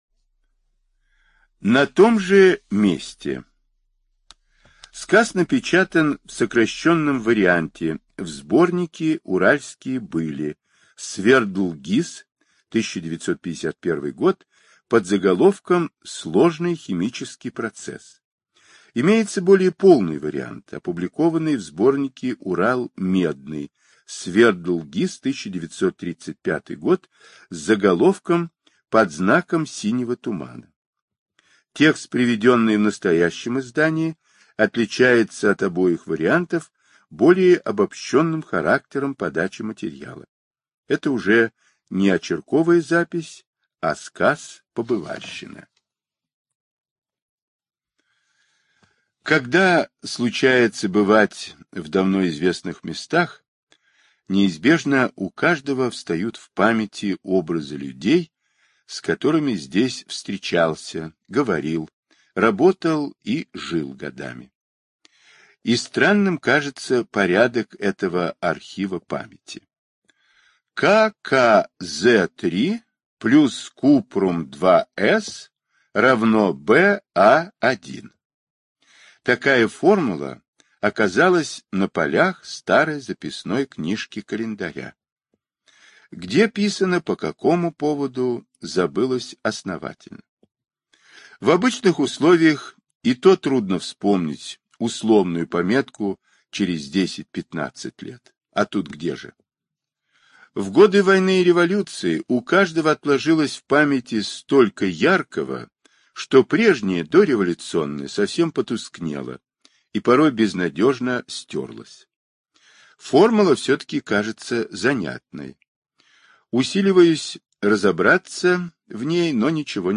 Сказки для детей > Аудиосказки > Павел Бажов: Аудиосказки > Павел Бажов — На том же месте Павел Бажов — На том же месте: Слушать A Уменьшить шрифт.